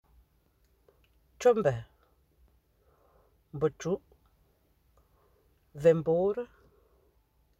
Accueil > Prononciation > b > b